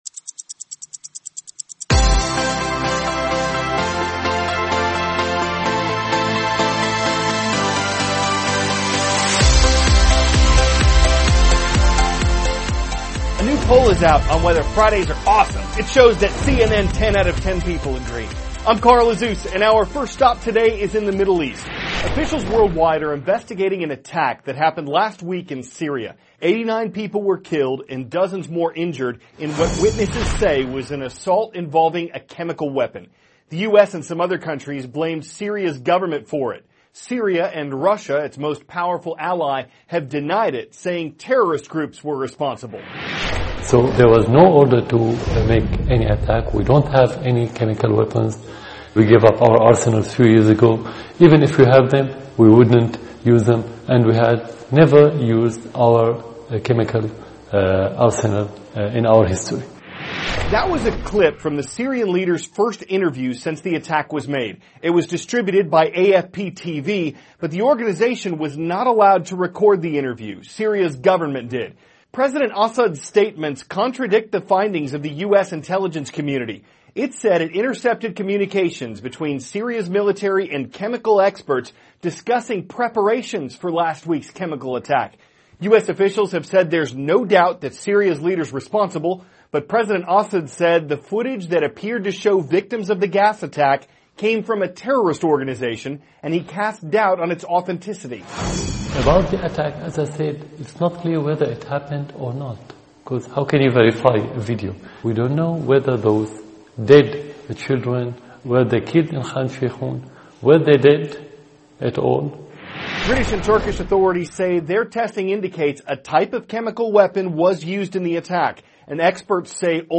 *** CARL AZUZ, cnn 10 ANCHOR: A new poll is out on whether Fridays are awesome!